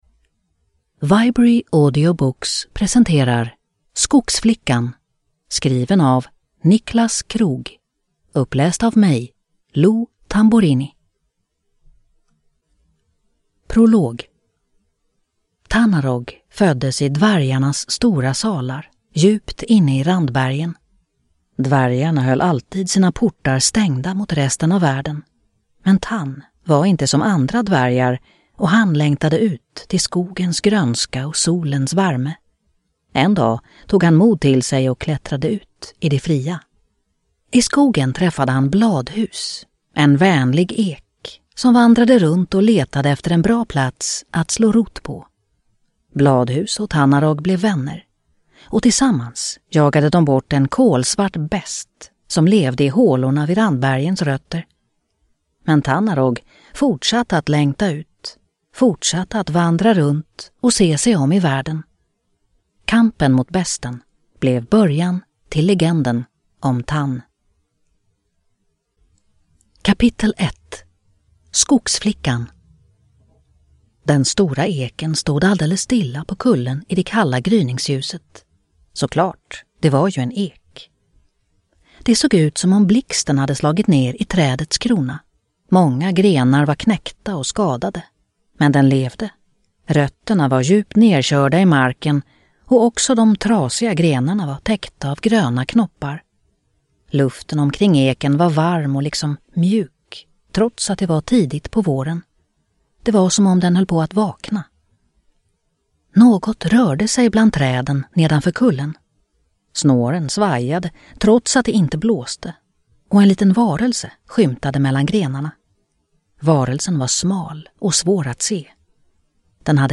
Skogsflickan – Ljudbok